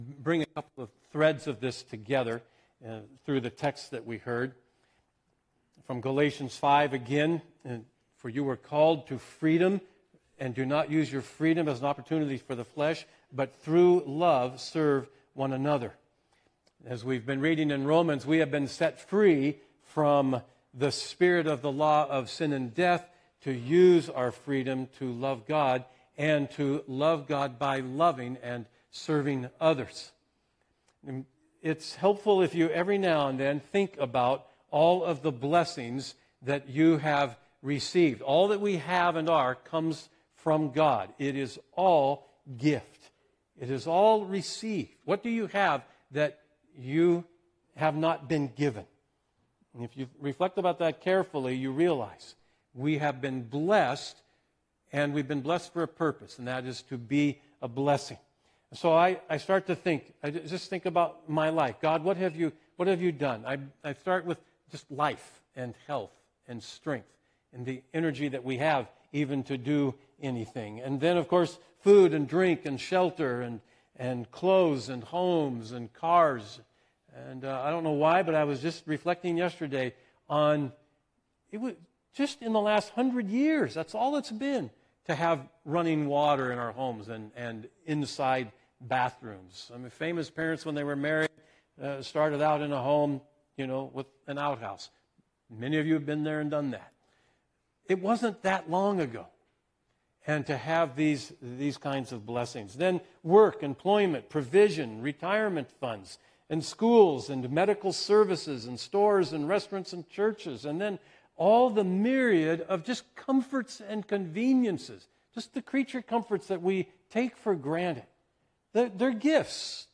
Text for Sermon: Malachi 1:1, 6; 11-14; 3:6-10; 4:5-6